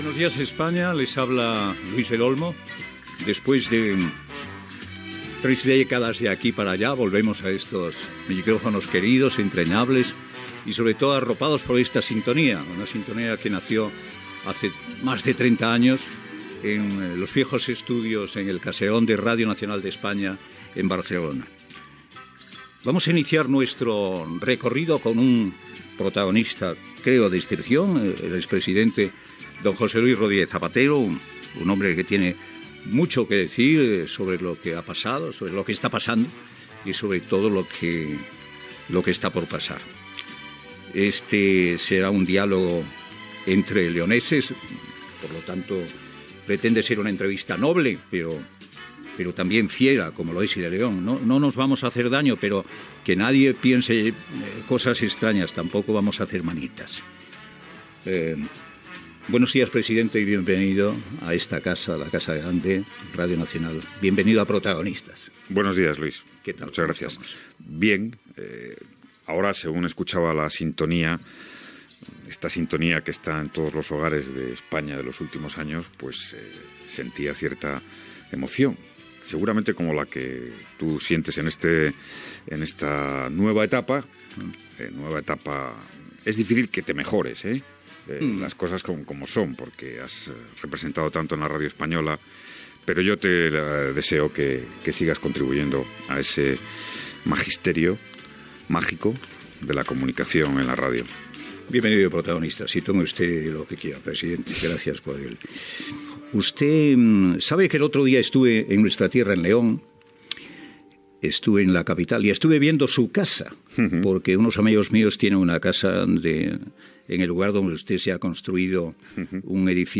Primera edició de Protagonistas en la seva segona etapa a RNE, amb un fragment d'una entrevista a l'ex president José Luis Rodríguez Zapatero.
Info-entreteniment